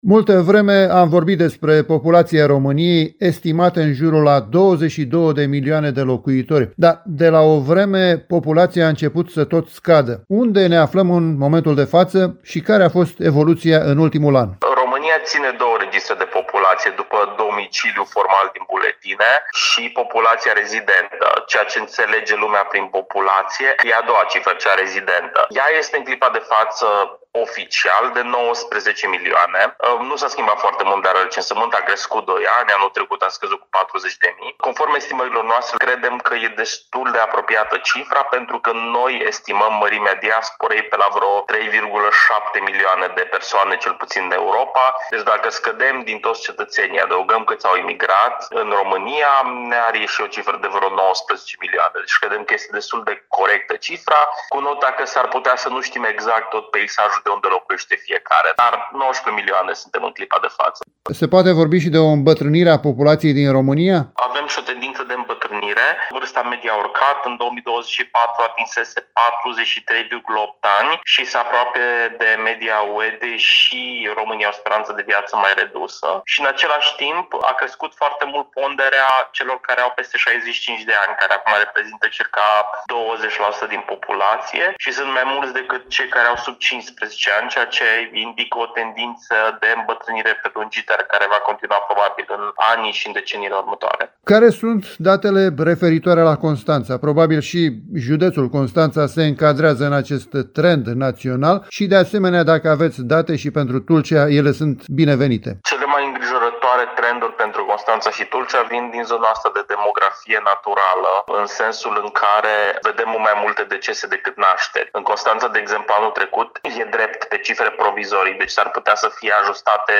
inteerviu